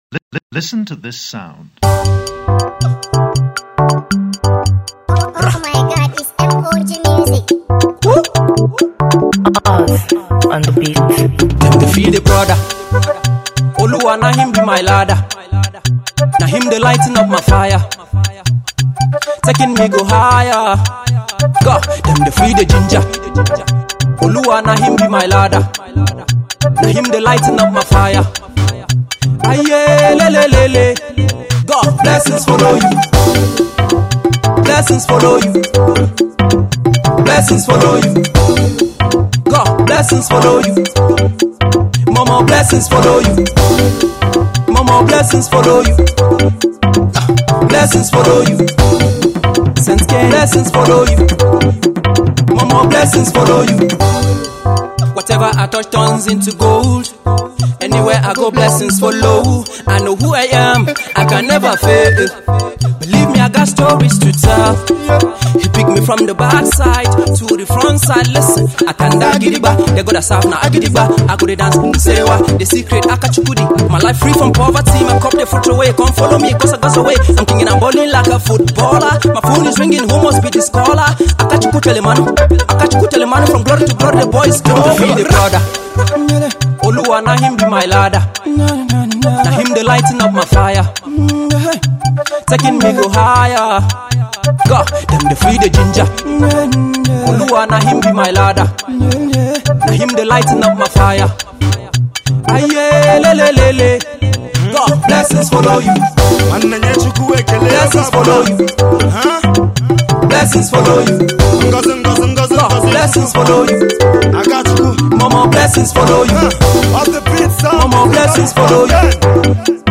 dance-hall track